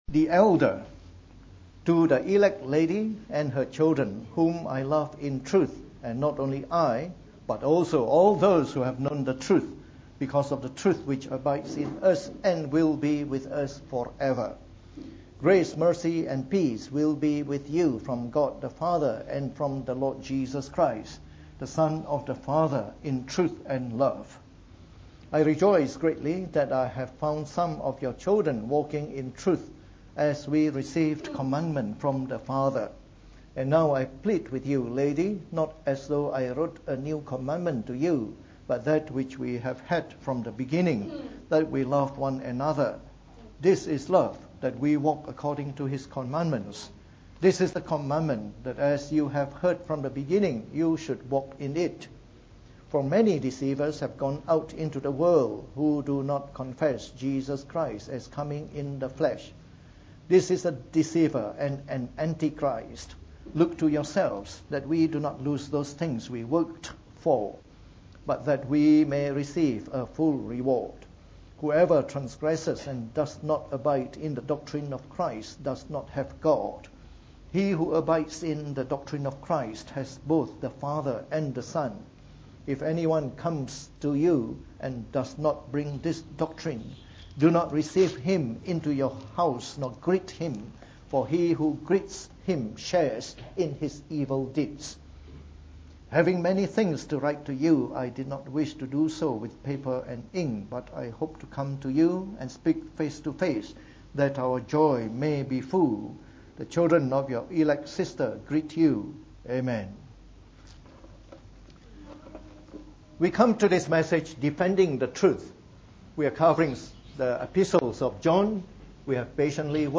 From our series on the Book of 2 John delivered in the Morning Service.